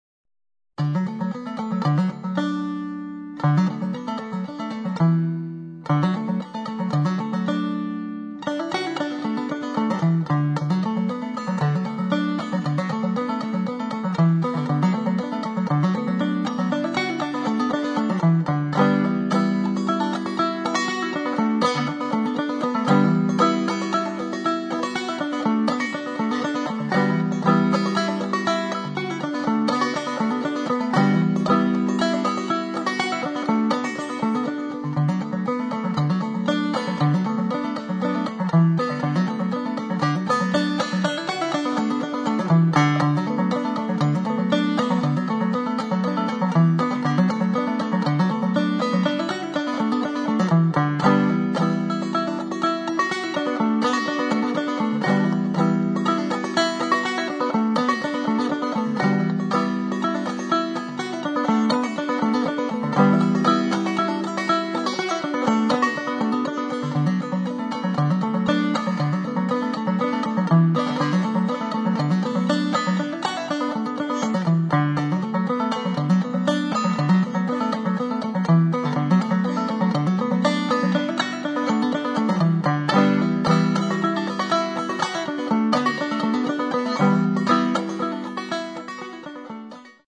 clawhammer banjo instrumental